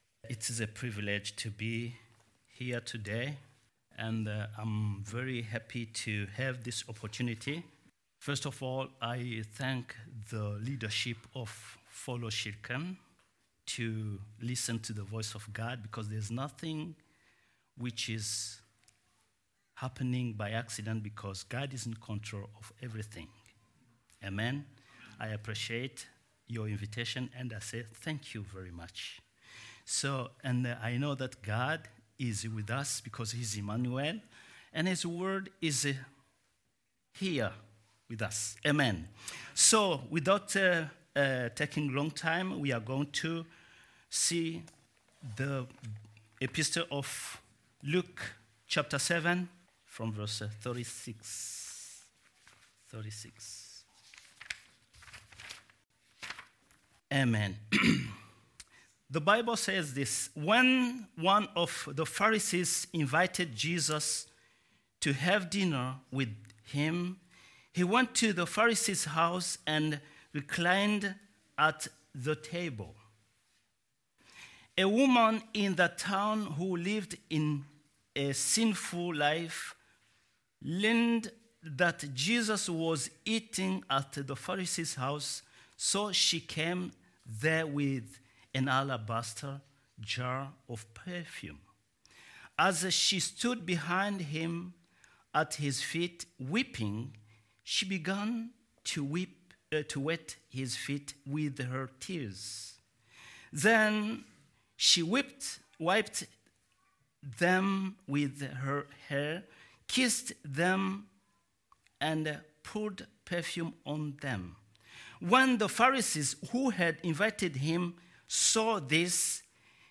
Sermons | GFC